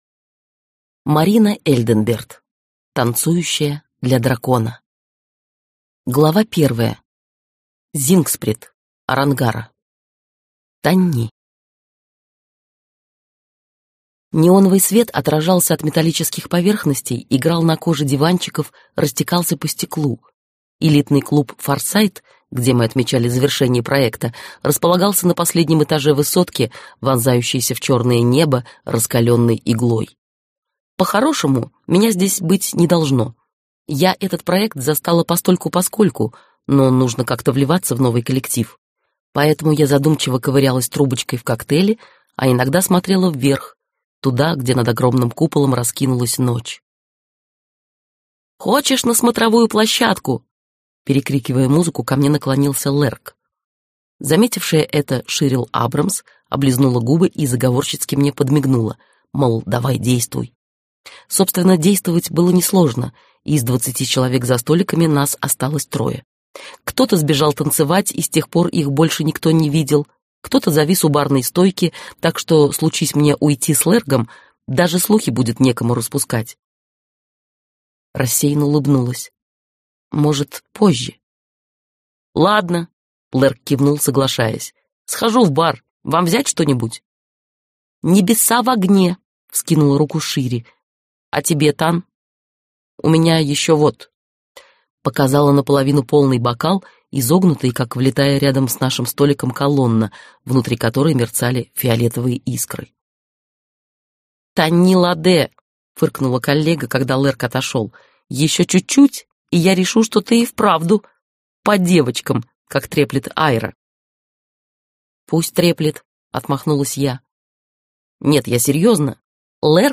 Аудиокнига Танцующая для дракона - купить, скачать и слушать онлайн | КнигоПоиск